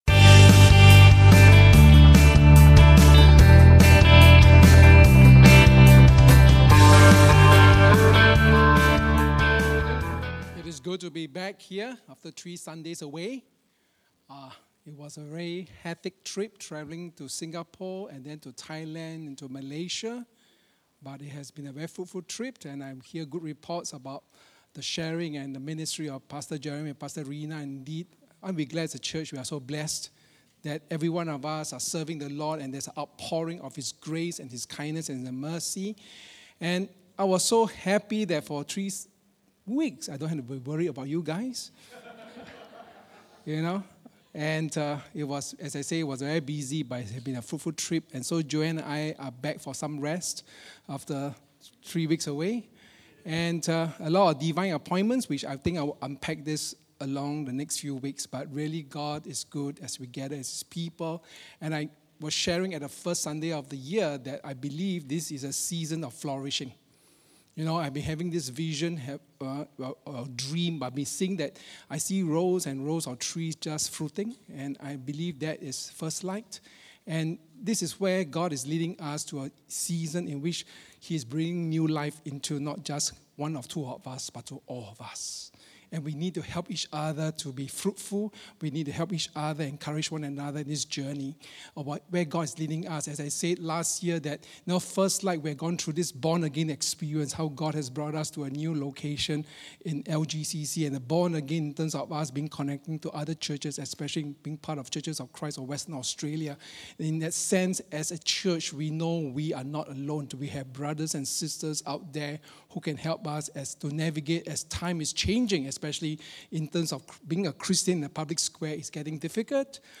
Sermons | Firstlight Church